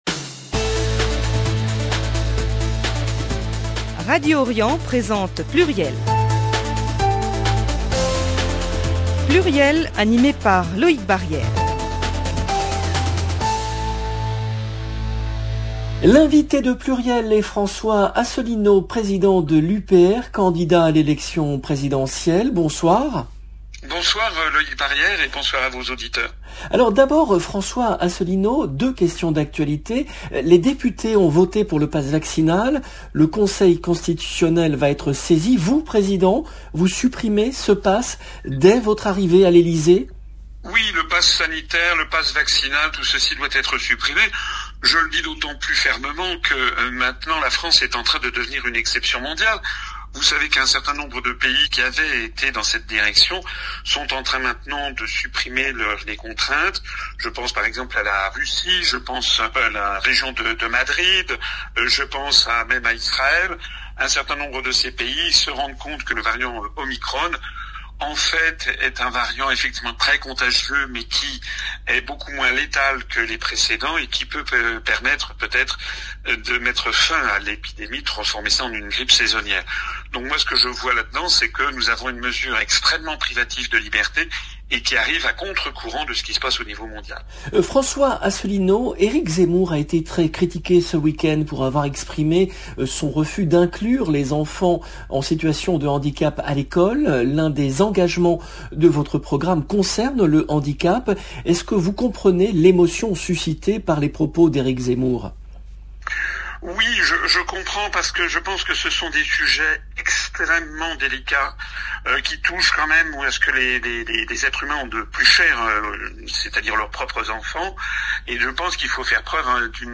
L’invité de PLURIEL est François Asselineau , président de l’UPR, candidat à l’élection présidentielle